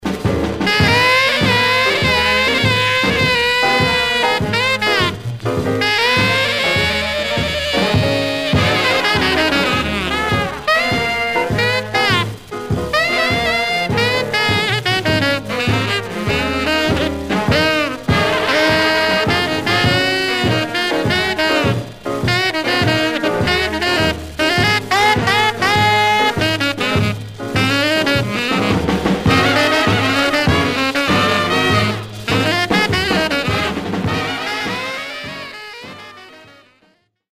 Some surface noise/wear Stereo/mono Mono
R&B Instrumental